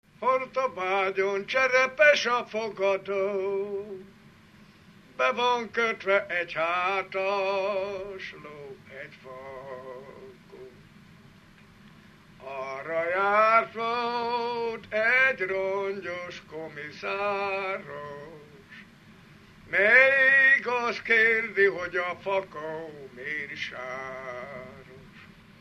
Alföld - Jász-Nagykun-Szolnok vm. - Karcag
ének
Dallamtípus: Dudanóta (11 szótagos) 2
Stílus: 6. Duda-kanász mulattató stílus
Kadencia: 5 (1) 2 1